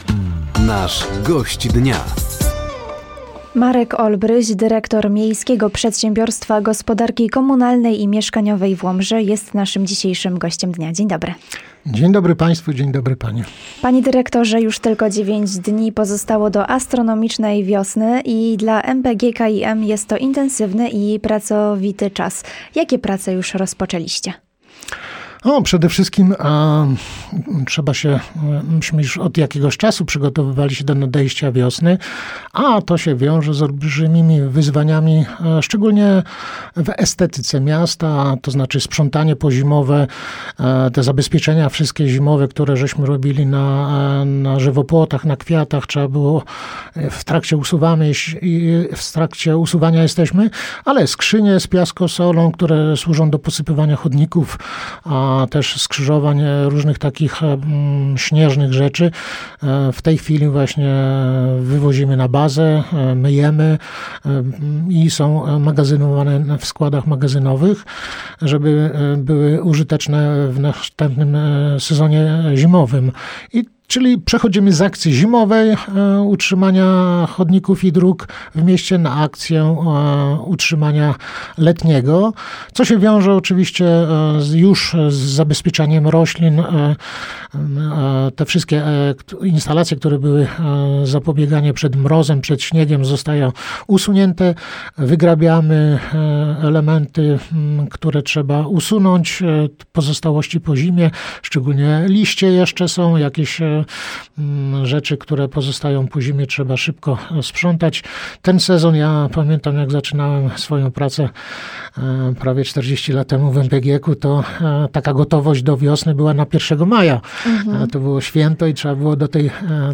Przygotowania do wiosennego sezonu, nasadzenia w mieście oraz podsumowanie akcji zimowej – to główne tematy rozmowy podczas audycji ,,Gość Dnia”.